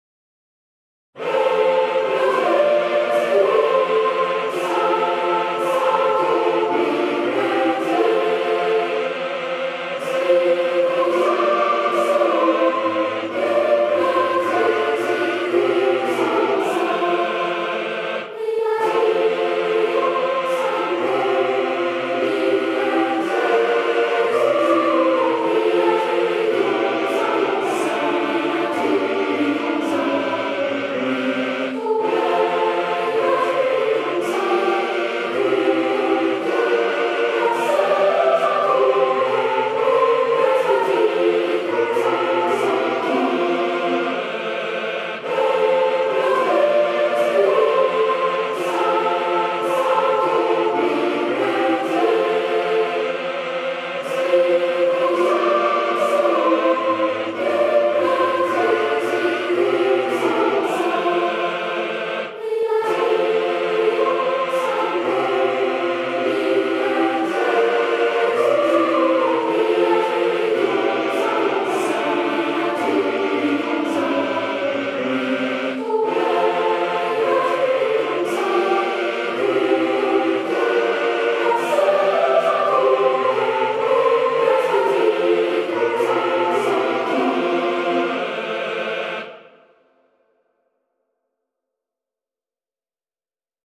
- Présentation : Cantique de la Santo Baumo, dédicacé à "Mounsegne JORDANY, évêque de Fréjus et de Toulon".